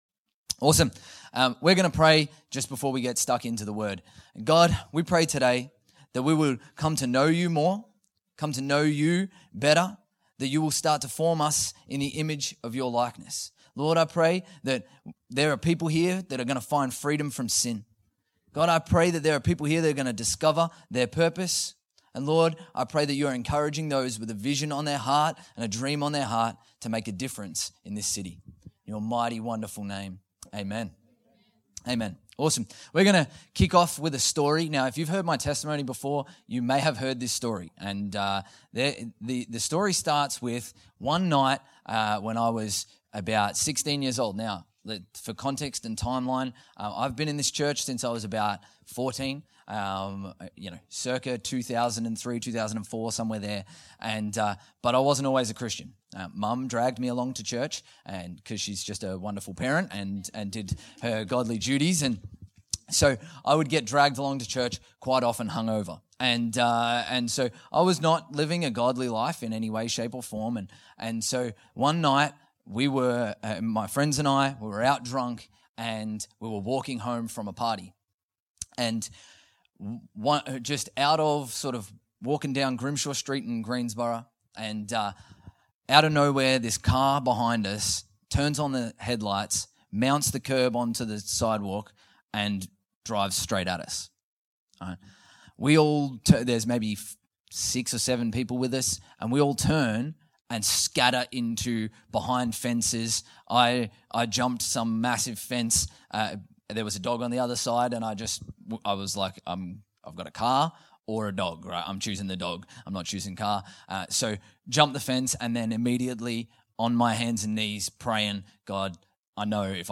Infinity Church Podcast - English Service | Infinity Church
Current Sermon